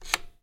Звук кукушки из старинных настенных часов
• Категория: Настенные и напольные часы с кукушкой
• Качество: Высокое